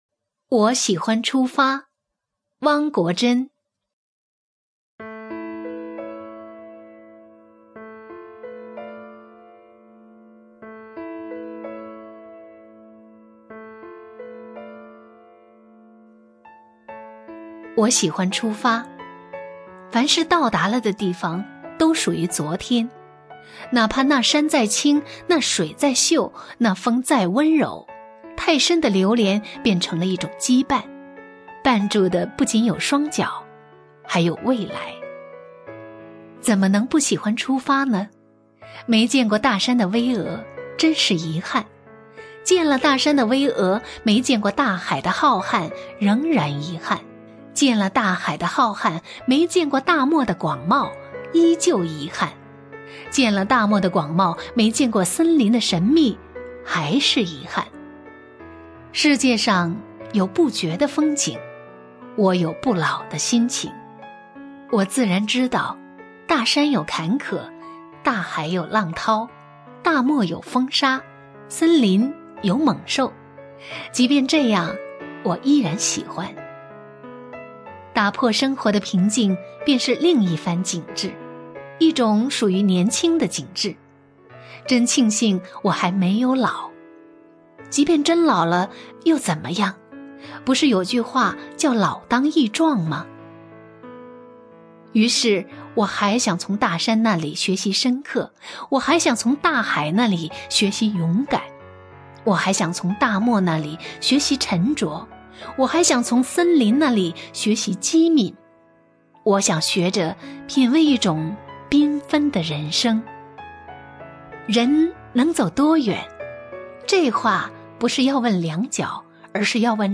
首页 视听 名家朗诵欣赏 欧阳婷
欧阳婷朗诵：《我喜欢出发》(汪国真)